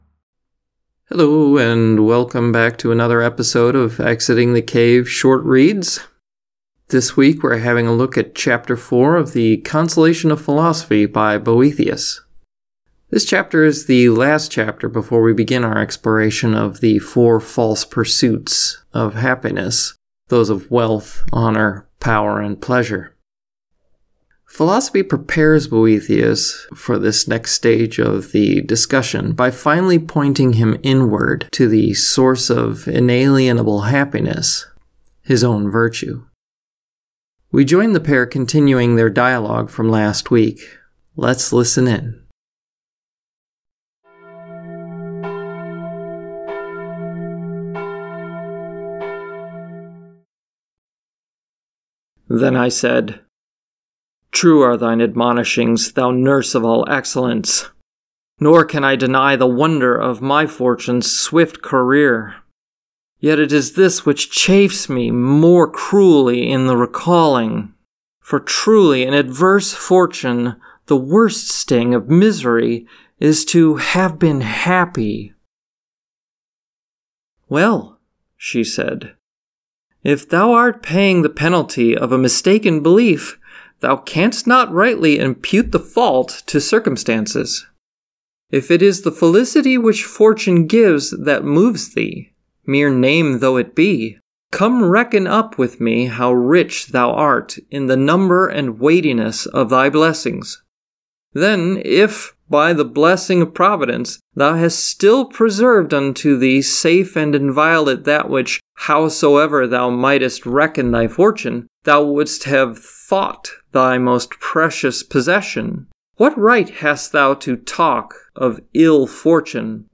I do a reading of Shakespeare’s Hamlet.